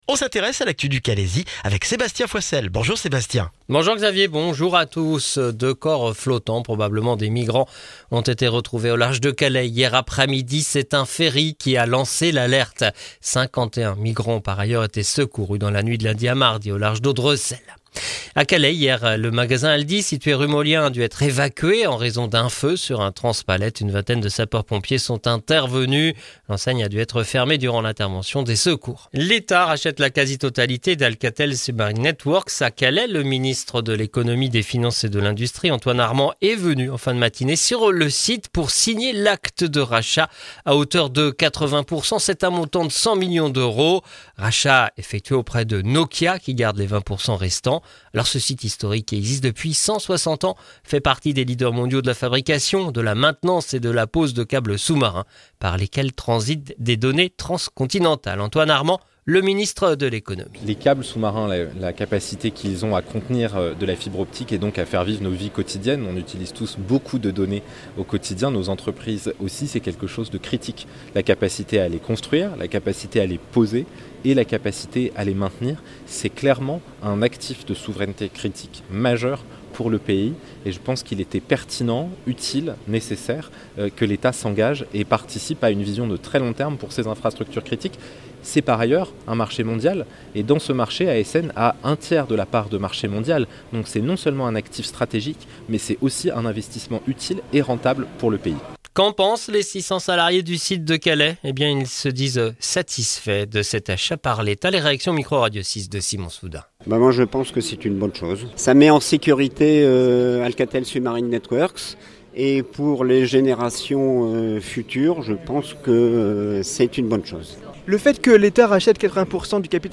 Le journal du mercredi 6 novembre dans le Calaisis